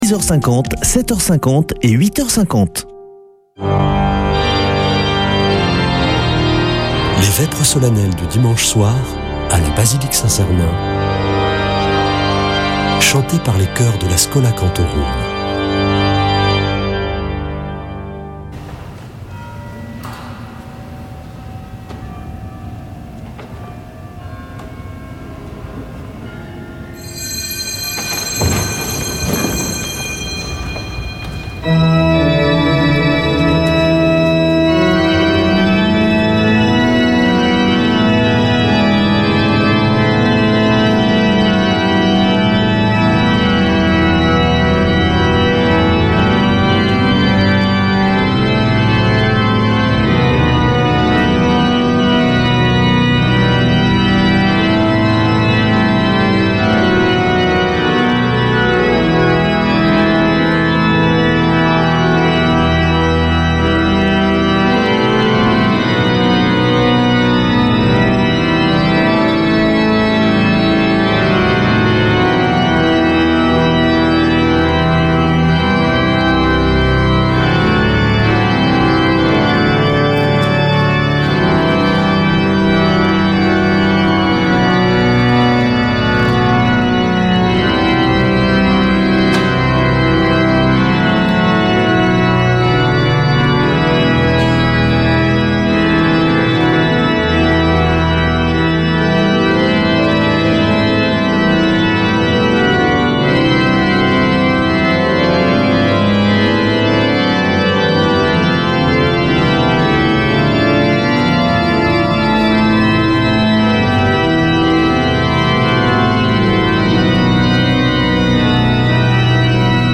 Vêpres de Saint Sernin du 23 avr.
Une émission présentée par Schola Saint Sernin Chanteurs